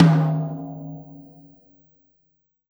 Tom Shard 08.wav